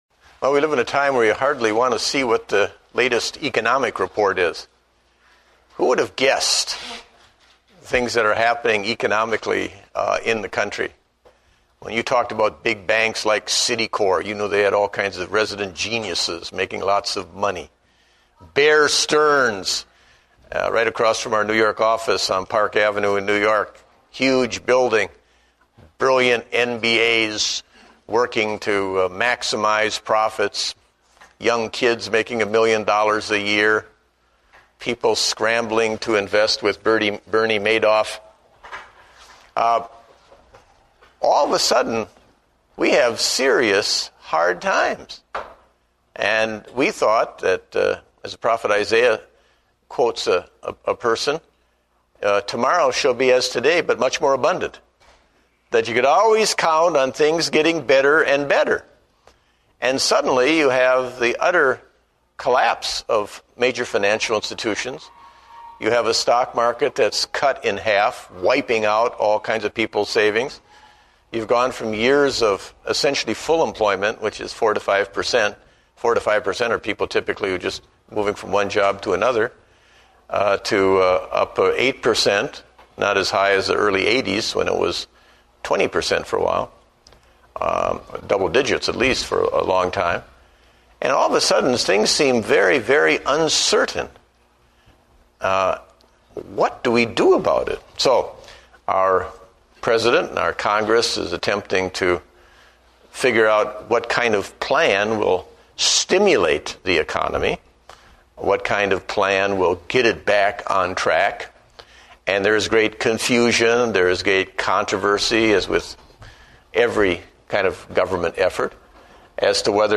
Date: March 15, 2009 (Adult Sunday School)